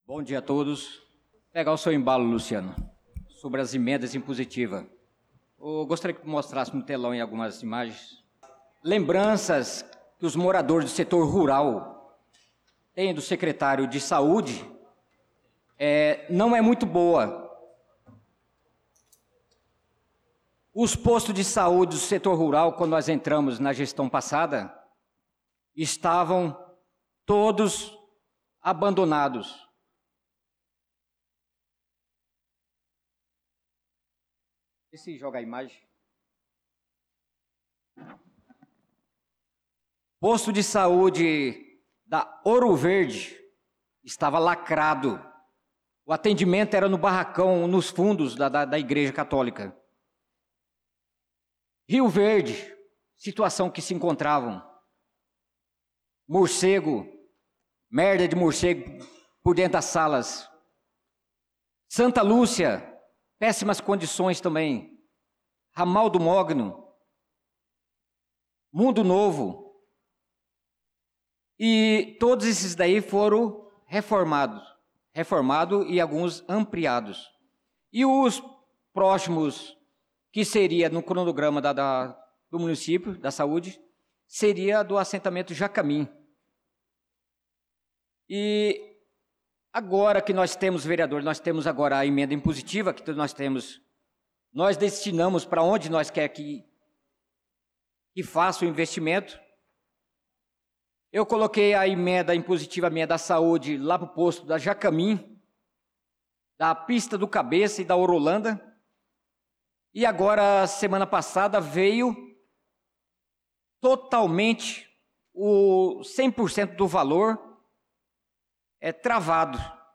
Pronunciamento do vereador Naldo da Pista na Sessão Ordinária do dia 05/05/2025